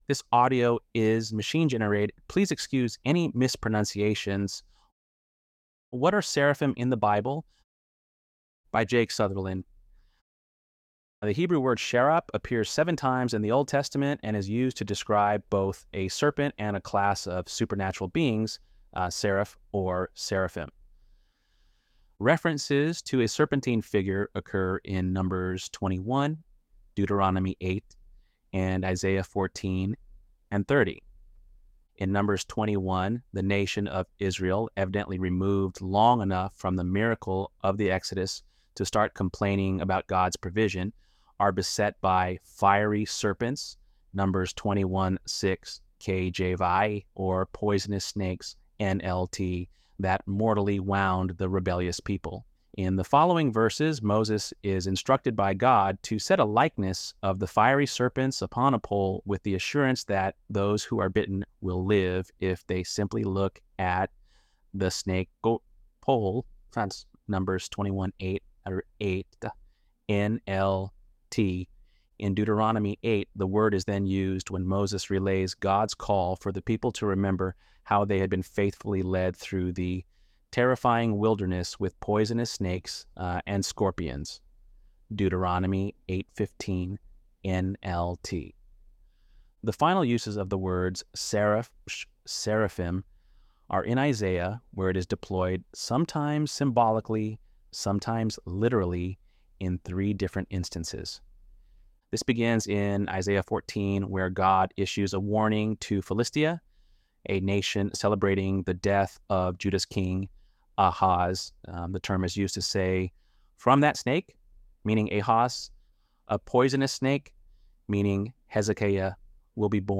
ElevenLabs_12_20.mp3